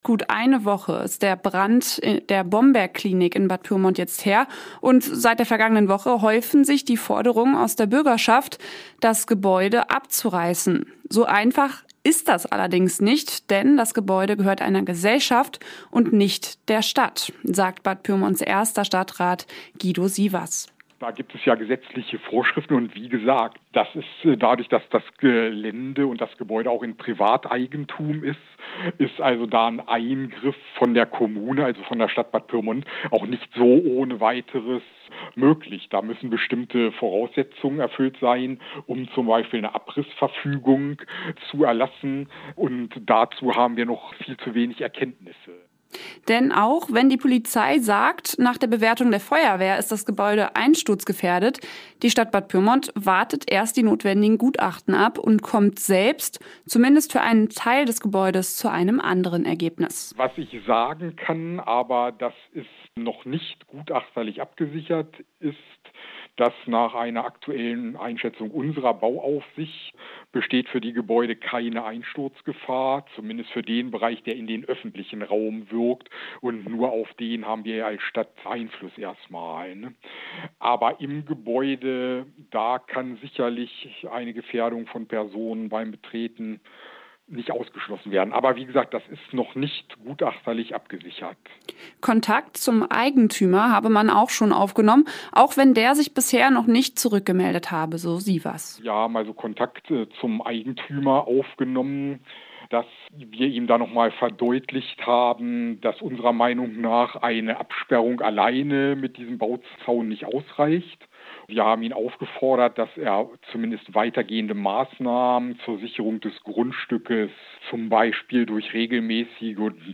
Aktuelle Lokalbeiträge Bad Pyrmont: STADT ZUR BOMBERGKLINIK Play Episode Pause Episode Mute/Unmute Episode Rewind 10 Seconds 1x Fast Forward 30 seconds 00:00 / Download file | Play in new window Bad Pyrmonts erster Stadtrat Guido Sievers zum Brand in der verlassenen Bombergklinik in Bad Pyrmont. Bei der Stadt wird aktuell auf Gutachten zur Einsturzgefahr gewartet.